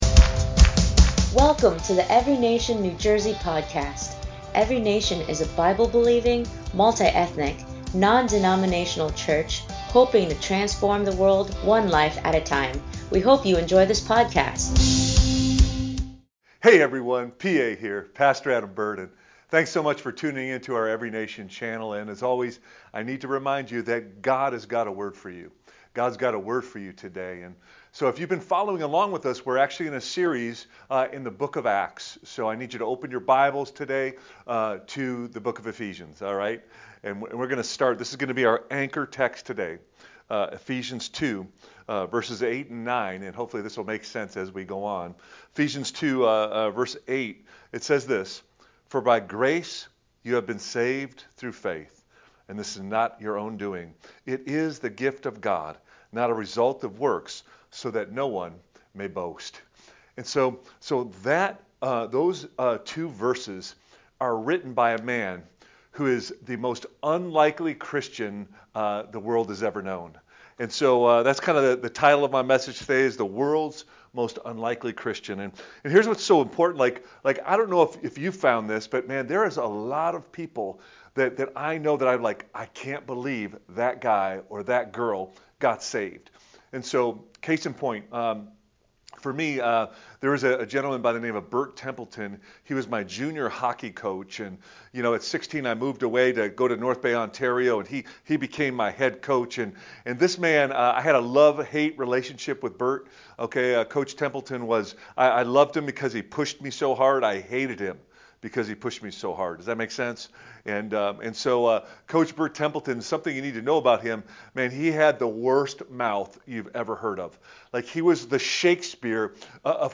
2025 THE WORLD’S MOST UNLIKELY CHRISTIAN Preacher